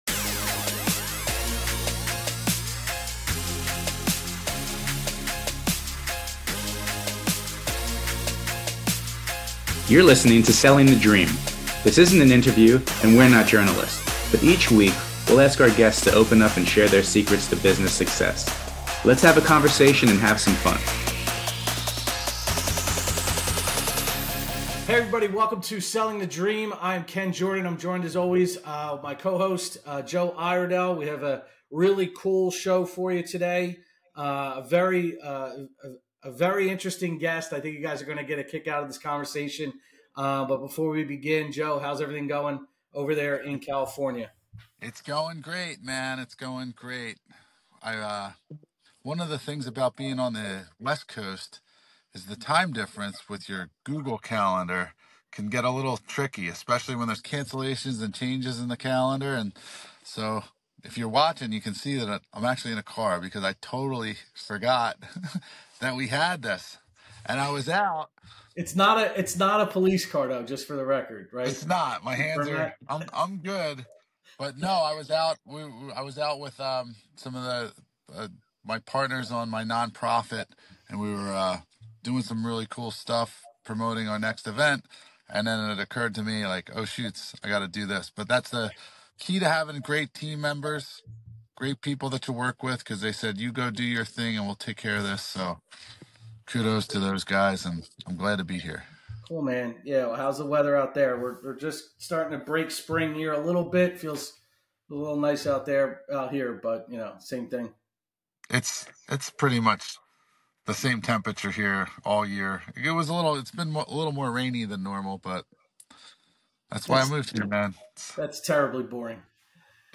Let's have a conversation and have some fun.